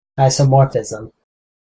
• / ˌaɪsʌˈmɔrfɪzʌm(米国英語)